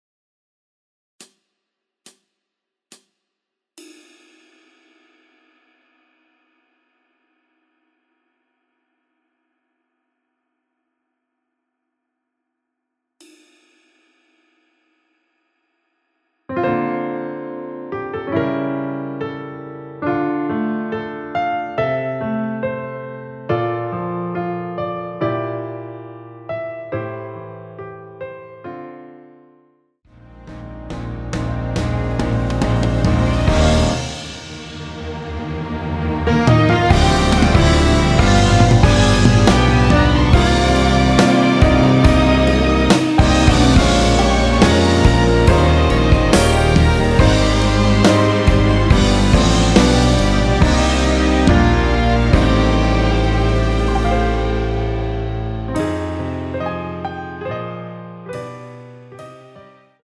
원키에서(-5)내린 MR입니다.
앞부분30초, 뒷부분30초씩 편집해서 올려 드리고 있습니다.